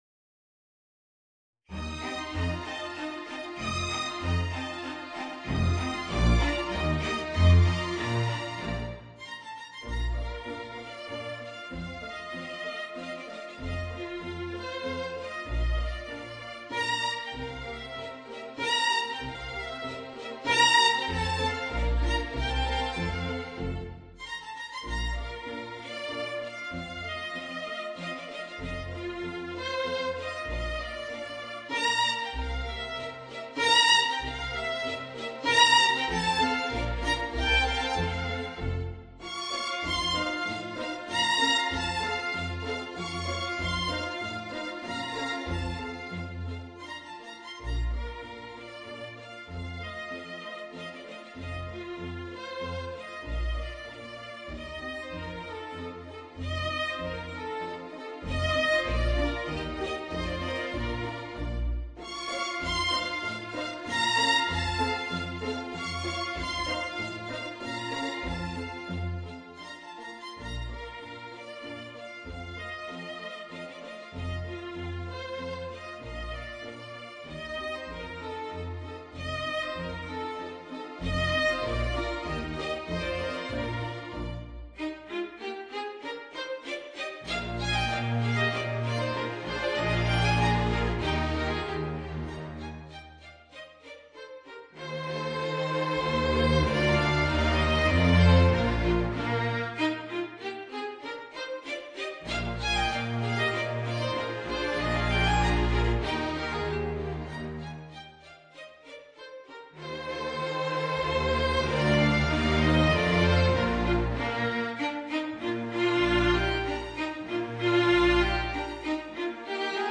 Voicing: Clarinet and String Quintet